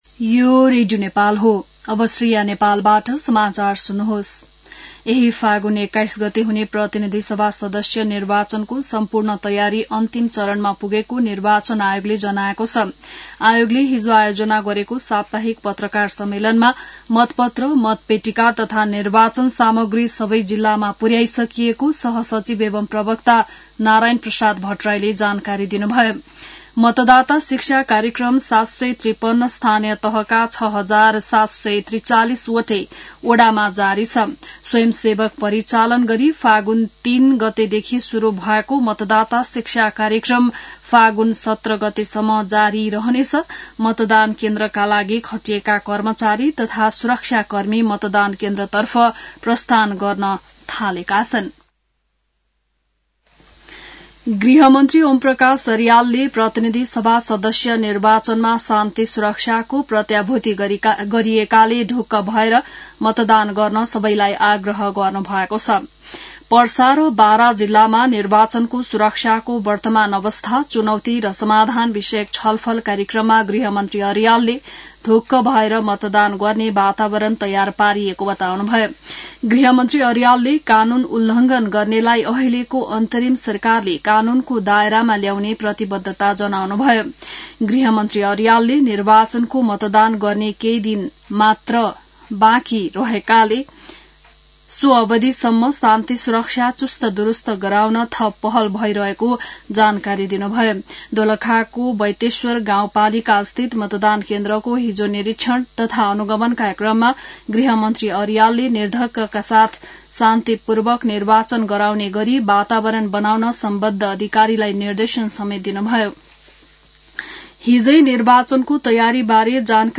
बिहान ६ बजेको नेपाली समाचार : १६ फागुन , २०८२